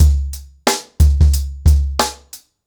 TrackBack-90BPM.65.wav